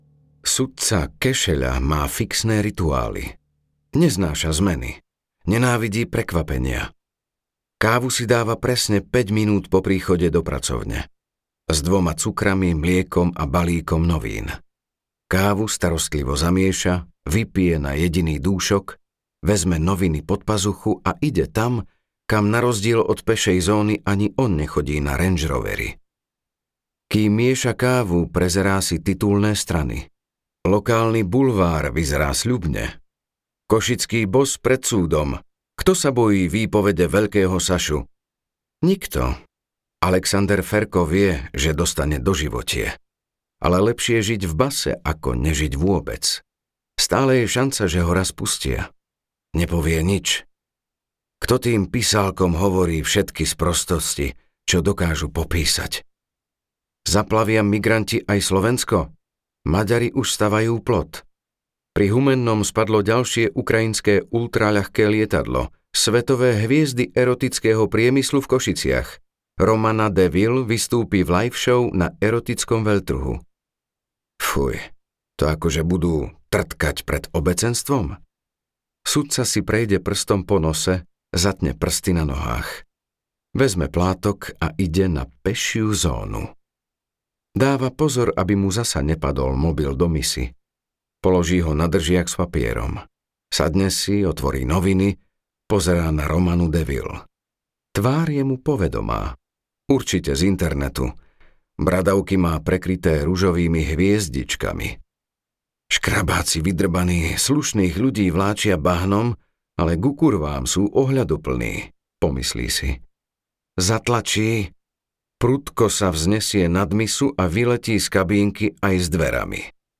Mäso audiokniha
Audiokniha Mäso, ktorú napísal Arpád Soltész. Koniec zlatých deväťdesiatych rokov, divoký východ Slovenska.
Ukázka z knihy